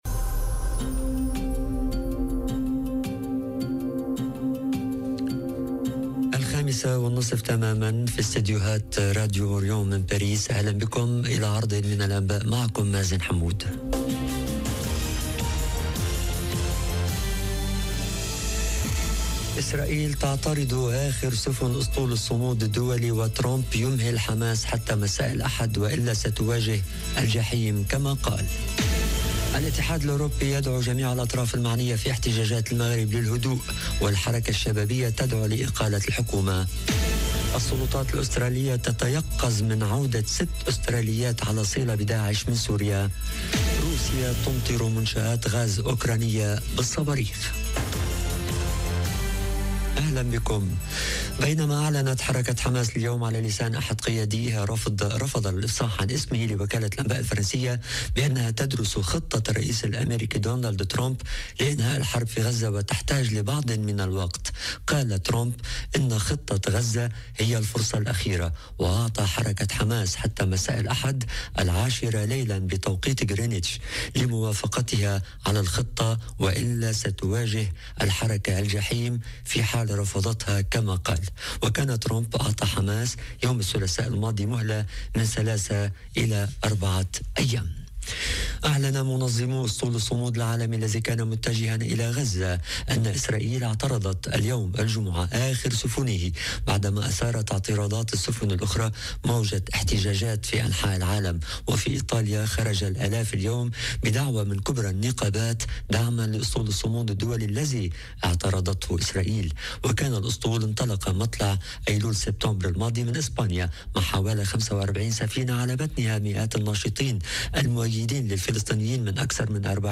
نشرة أخبار المساء: 150 طفلا توفوا بسبب سوء التغذية والمجاعة في قطاع غزة، لترتفع حصيلة الوفيات نتيجة المجاعة وسوء التغذية إلى 453.... - Radio ORIENT، إذاعة الشرق من باريس